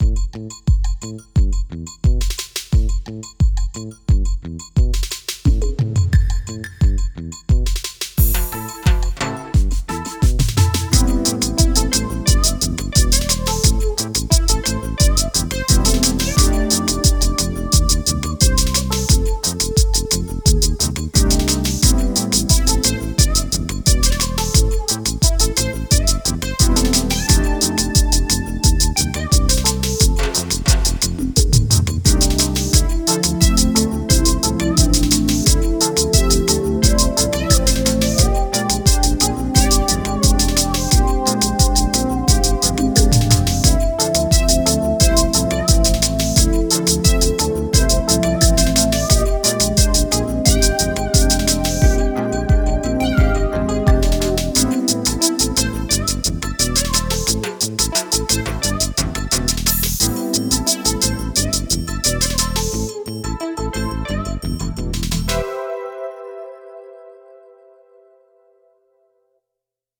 • On-Board Demos